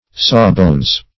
Sawbones \Saw"bones`\, n.